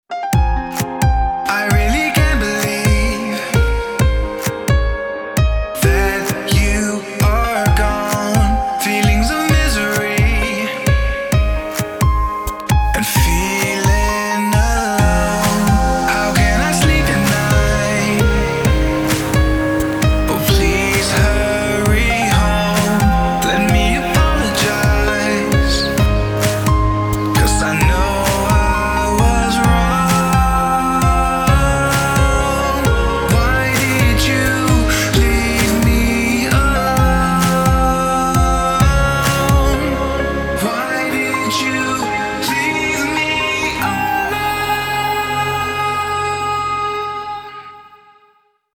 • Качество: 320, Stereo
пианино
нежные